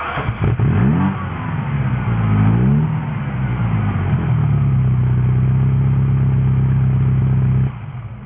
Starting up Drive By RedLine
Ferrari308QVStartup.wav